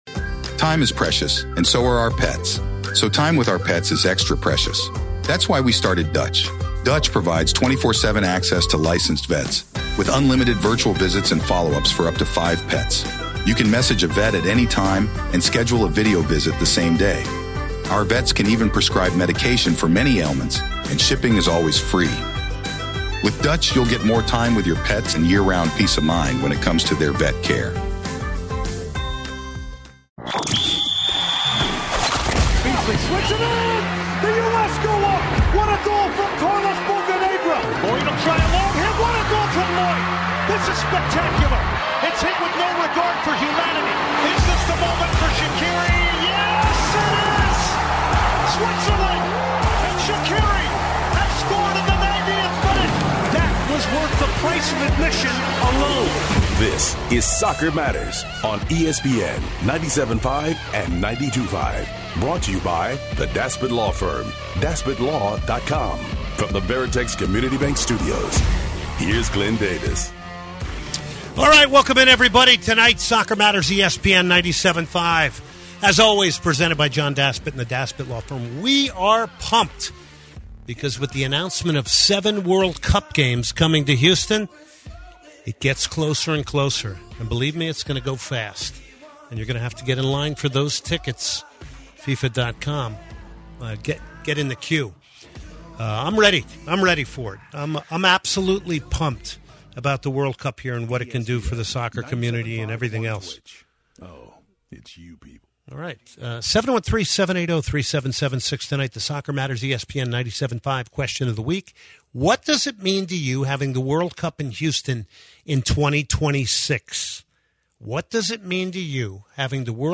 Soccer Matters callers celebrate Houston hosting 7 FIFA World Cup matches! 2/6/2024